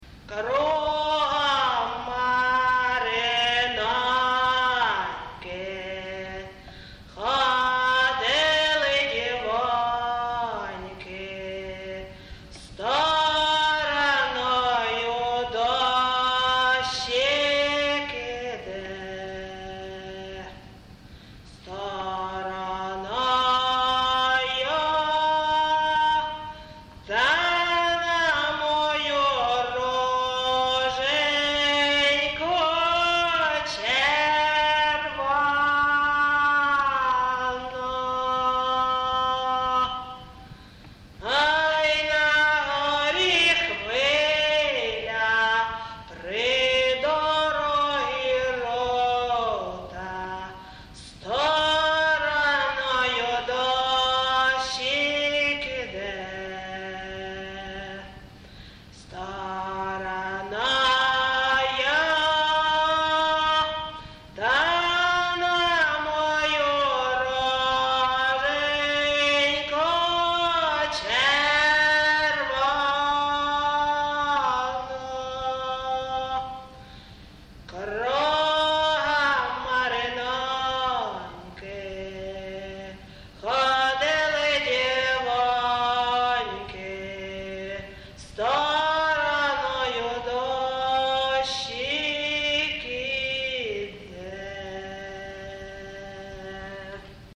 ЖанрКупальські
Місце записус. Ковалівка, Миргородський район, Полтавська обл., Україна, Полтавщина